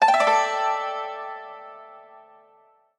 和風
効果音 ＞ 和風